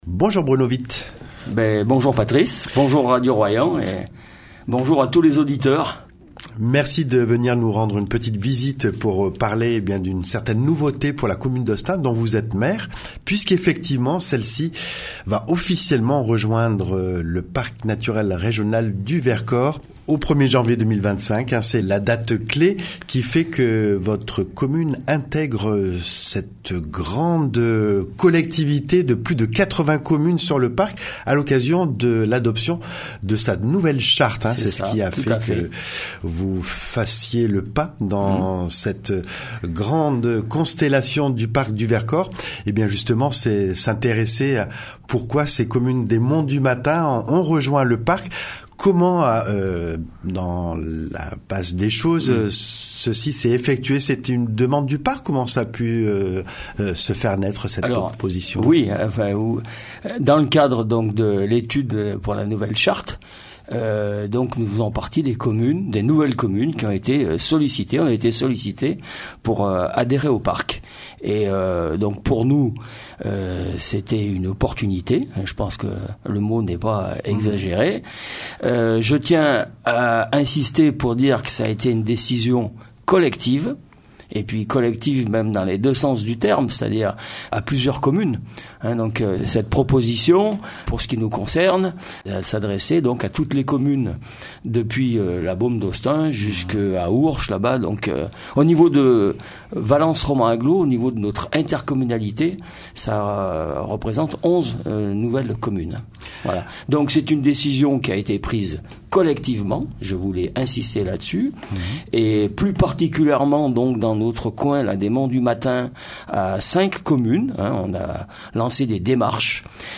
Bruno Vitte, maire d’Hostun, nous entretient à propos de la volonté d’intégrer le Parc à l’occasion de sa nouvelle charte et de rejoindre les 83 communes qui y siègent.